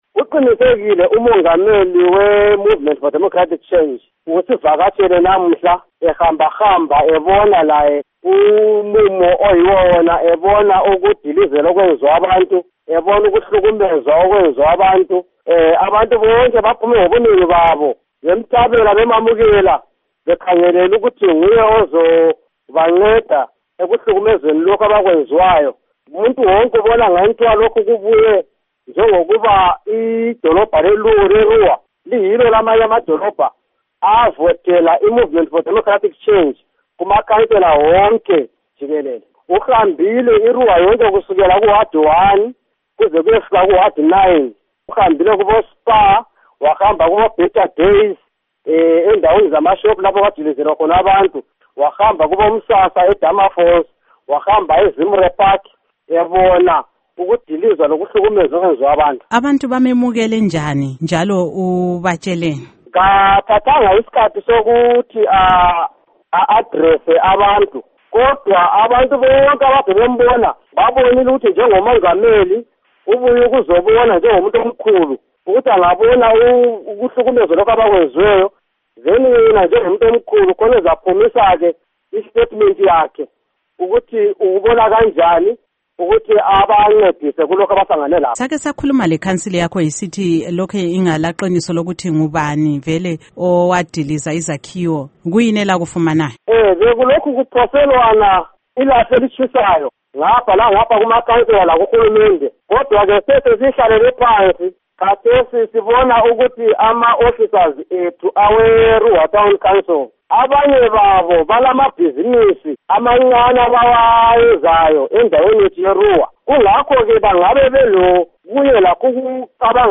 Ingxoxo LoMnu. Morgan Tsvangirai